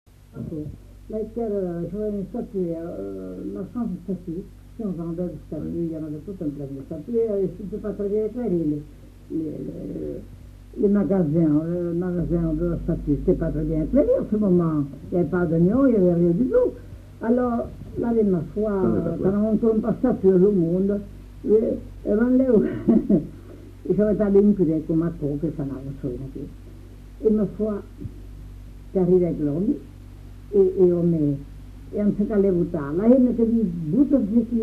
Aire culturelle : Marsan
Lieu : [sans lieu] ; Landes
Genre : conte-légende-récit
Effectif : 1
Type de voix : voix de femme
Production du son : parlé